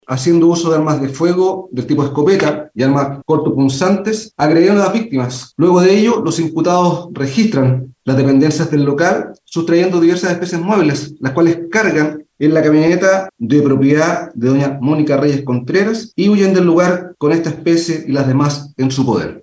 Durante la formalización, el fiscal Luis Ventura explicó que, tras los ataques, los delincuentes robaron diversas especies del local y luego escaparon en un vehículo que era de propiedad de una de las víctimas.